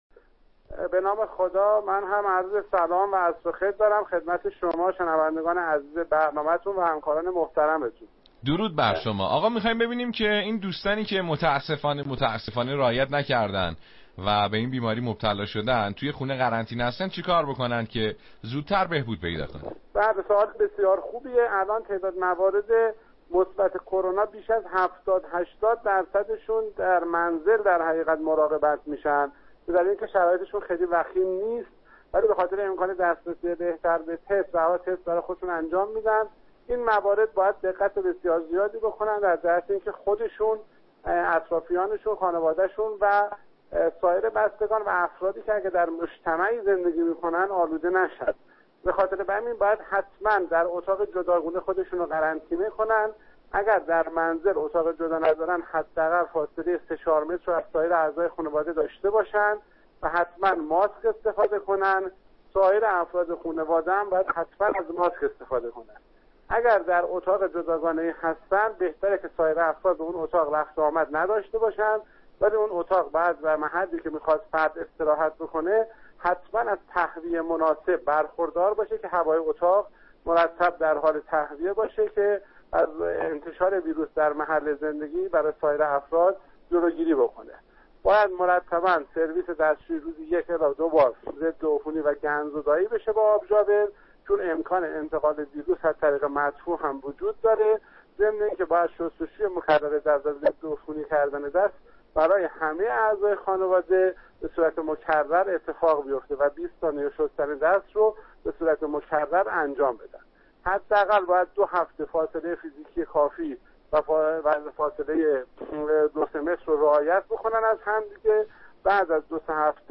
گفتگوی تلفنی با دکتر نظری رییس مرکز بهداشت استان و معاون بهداشتی دانشگاه علوم پزشکی اراک در برنامه موج آشنا - معاونت بهداشتی
برنامه رادیویی